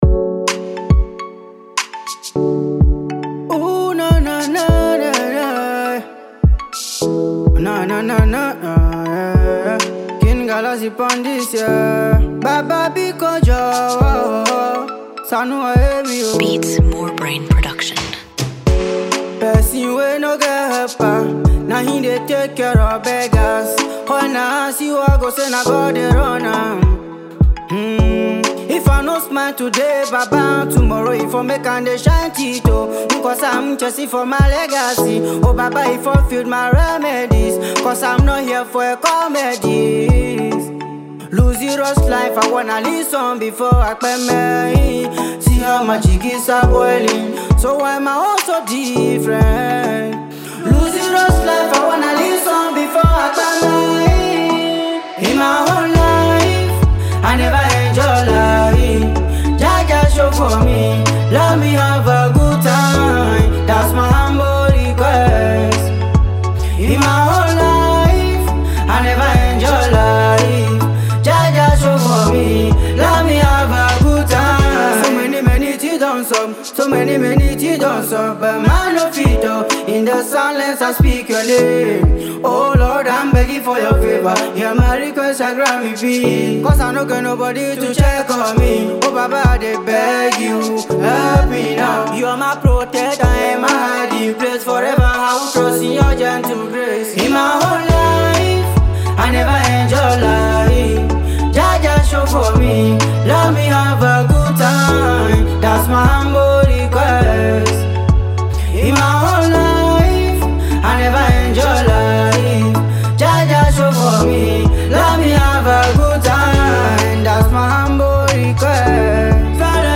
a heartfelt ballad
Ghana Music